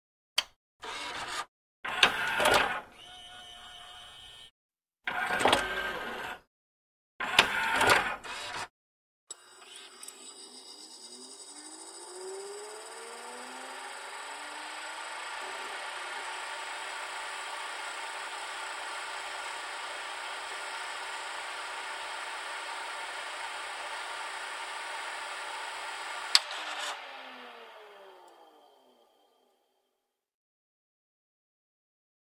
Звуки CD-плеера
Звук Серия звуков с лазерным диском, который засунули в CD-rom (для монтажа) (00:32)